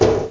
klonk1.mp3